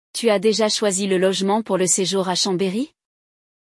LE DIALOGUE